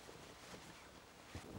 cloth_sail2.R.wav